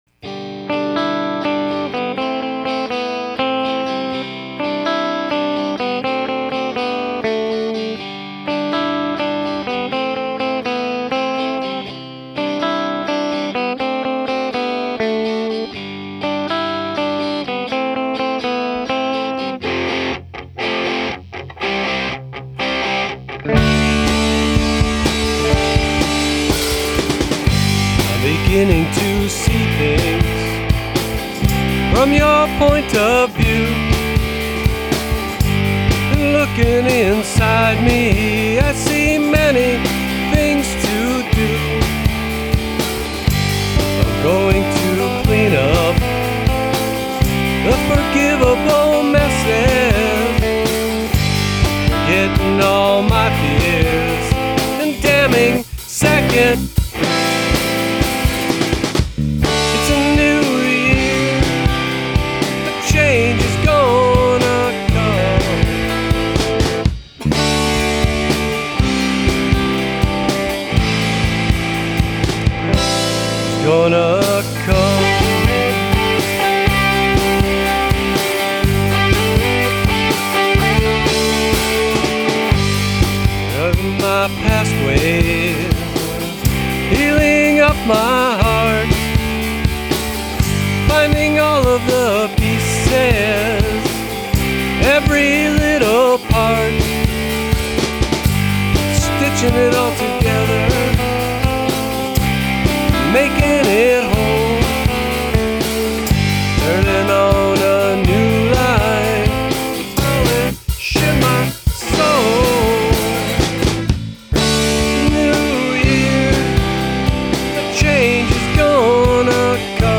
the loud ones